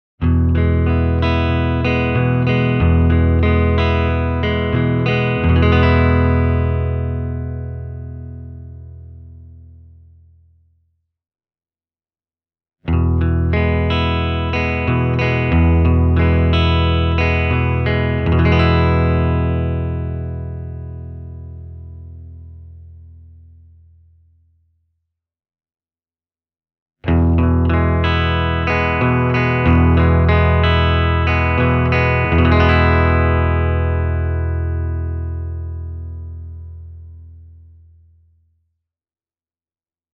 …an Epiphone Casino…
bluetone-black-prince-reverb-e28093-epiphone-casino.mp3